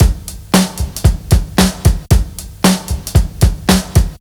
• 114 Bpm Drum Loop Sample G Key.wav
Free drum beat - kick tuned to the G note. Loudest frequency: 1550Hz
114-bpm-drum-loop-sample-g-key-Dq4.wav